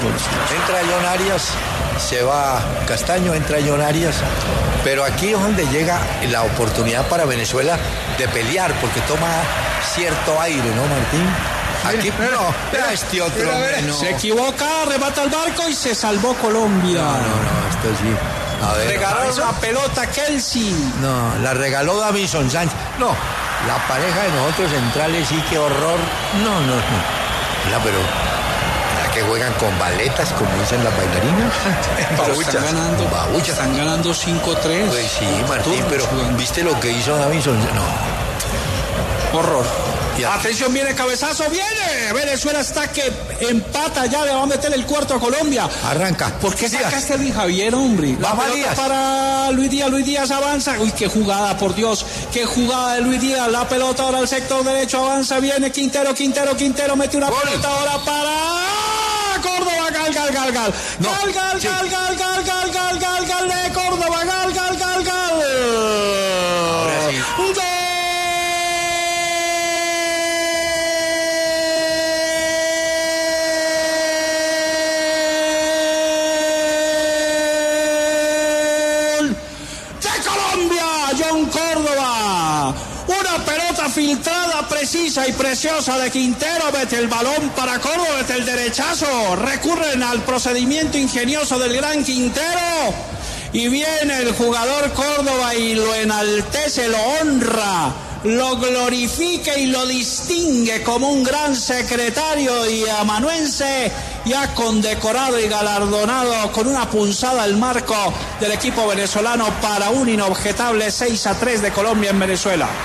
Martín de Francisco y Hernán Peláez narran el partido entre Colombia y Venezuela por la última fecha de las Eliminatorias por el Mundial 2026 en el Estadio Metropolitano de Maturín.
Reviva la narración del sexto gol de Colombia: